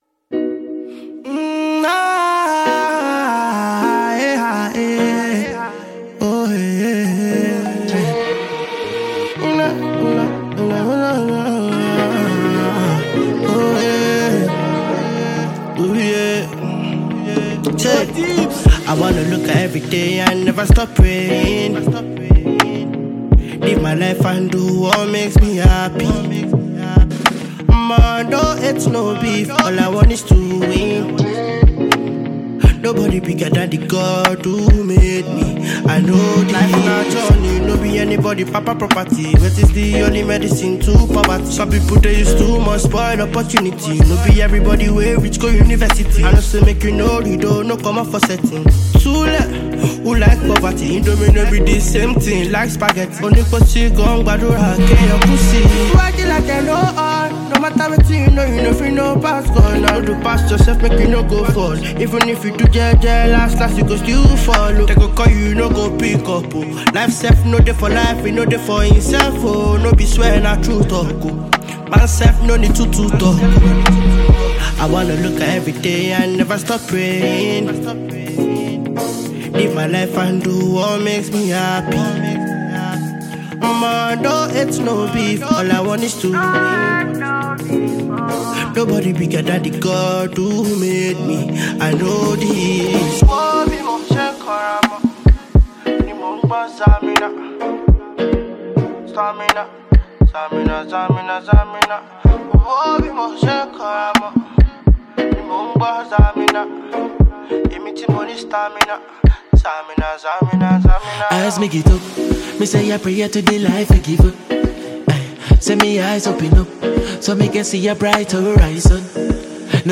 Afrobeats
Afrobeat with contemporary influences